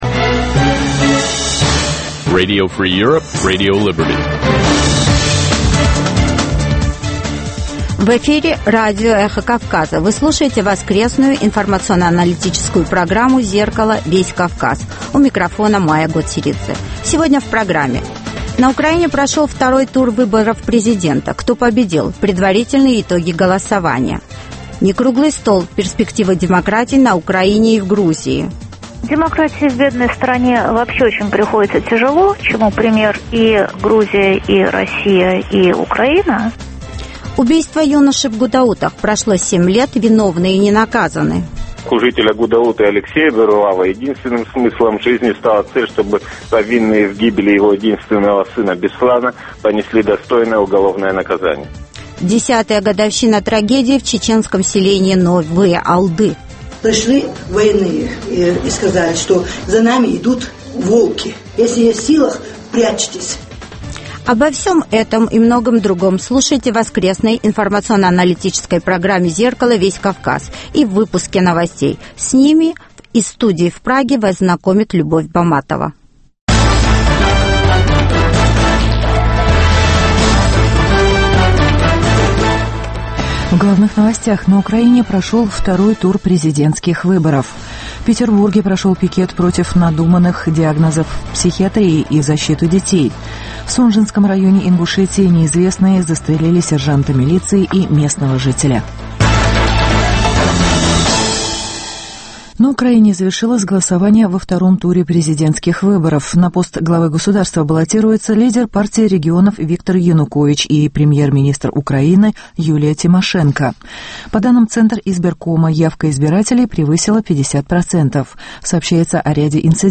Новости, репортажи с мест, интервью с политиками и экспертами , круглые столы, социальные темы, международная жизнь, обзоры прессы, история и культура.